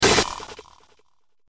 جلوه های صوتی
دانلود صدای ربات 64 از ساعد نیوز با لینک مستقیم و کیفیت بالا